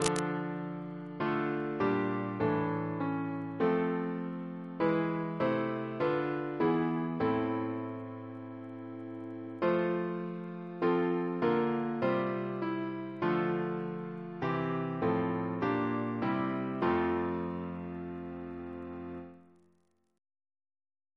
Double chant in F Composer: F. A. Gore Ouseley (1825-1889) Reference psalters: ACB: 372